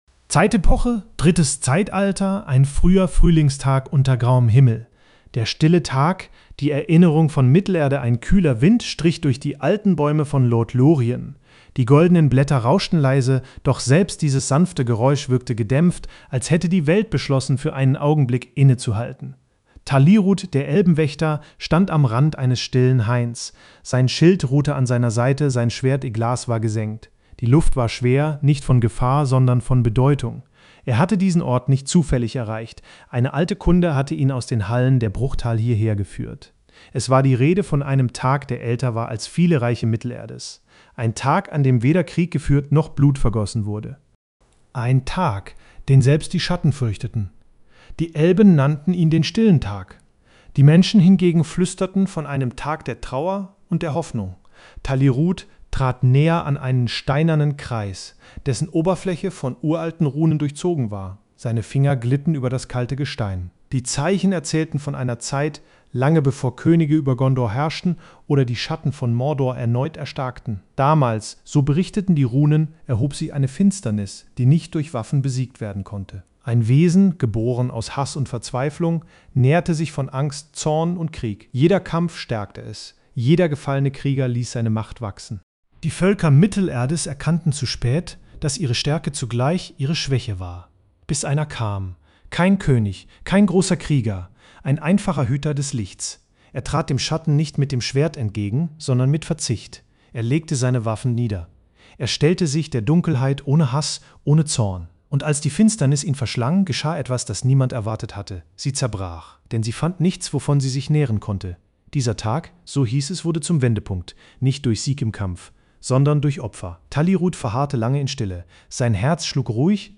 eine stimmungsvolle, ruhige Geschichte zum Karfreitag erleben
Diese Podcast-Folge wurde mithilfe einer KI-generierten Stimme